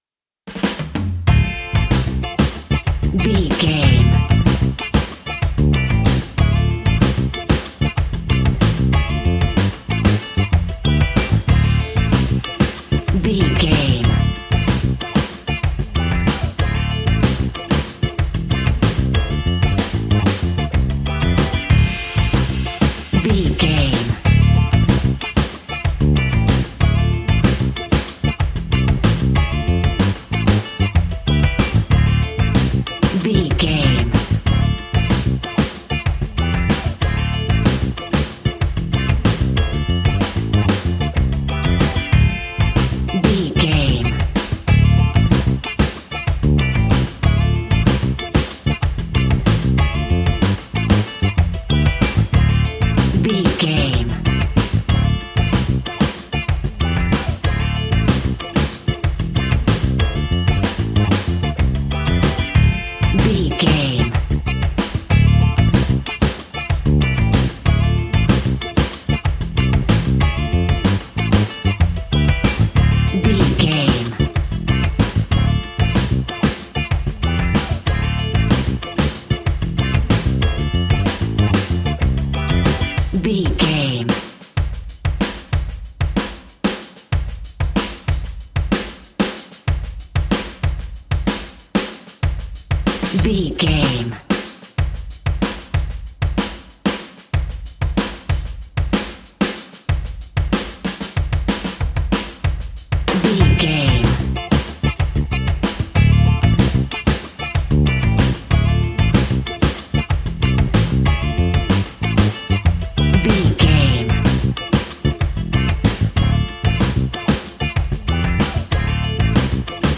Aeolian/Minor
lively
electric guitar
electric organ
saxophone
percussion